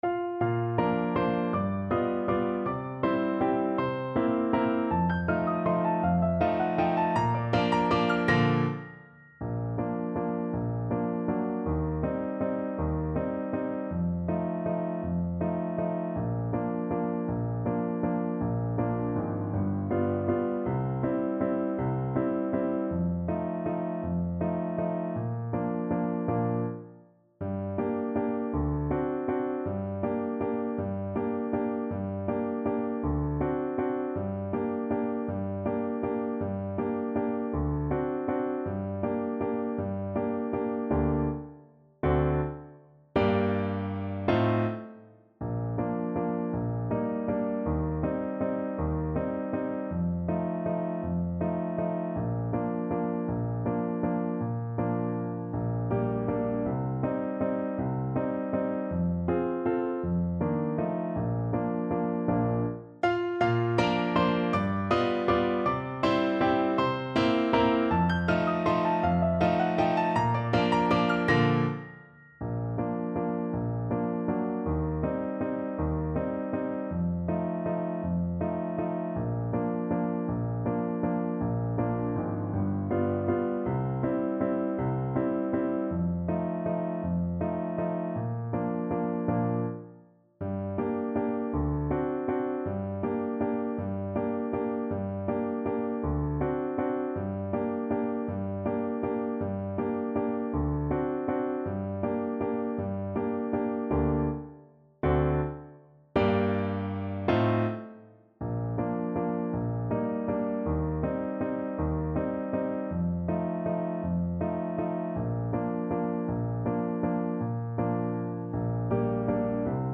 3/4 (View more 3/4 Music)
~ = 160 Tempo di Valse